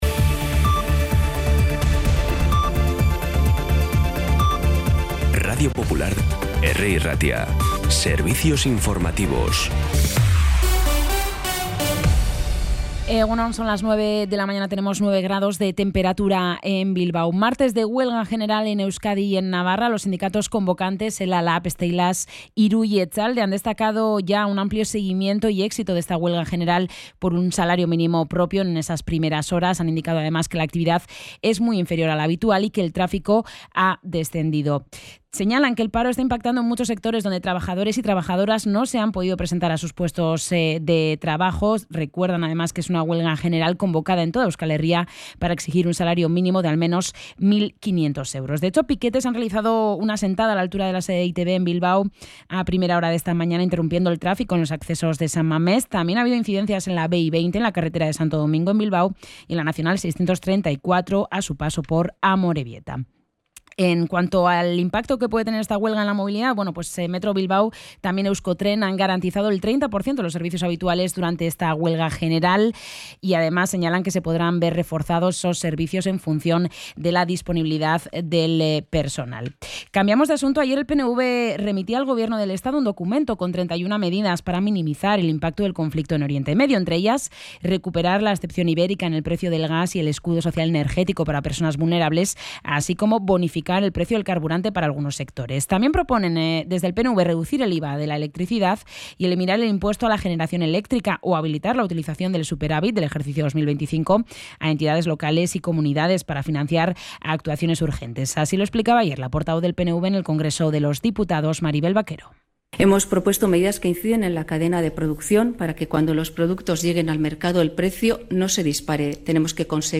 Las noticias de Bilbao y Bizkaia de las 9 , hoy 17 de marzo
Los titulares actualizados con las voces del día.